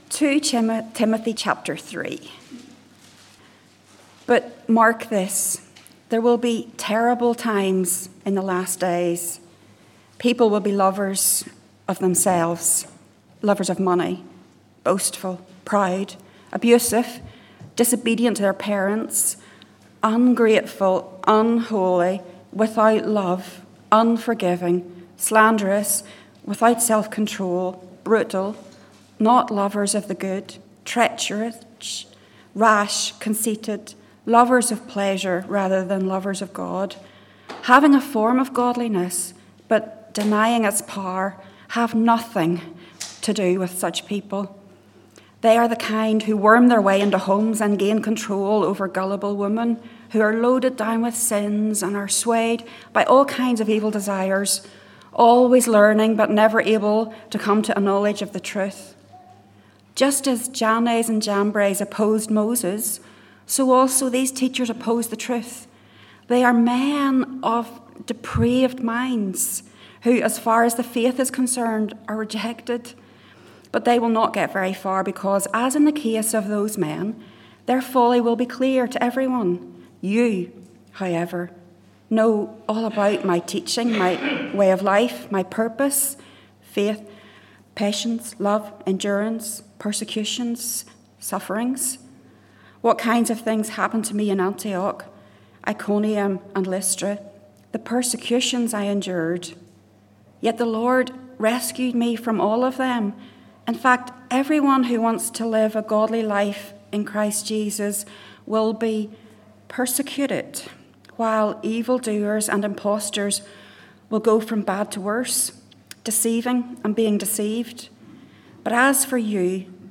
Service Type: Morning Service 9:15